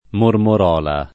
[ mormor 0 la ]